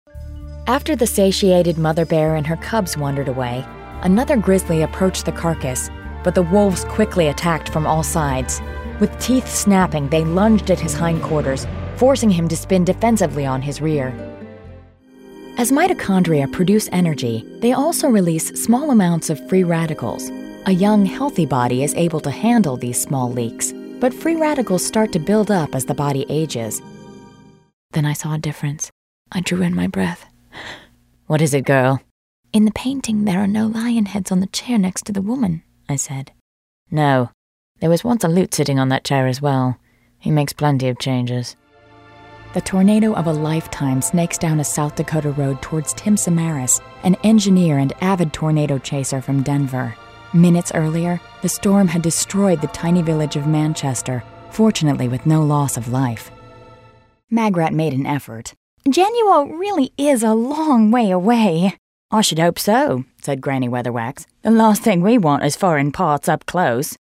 NarrDemo.mp3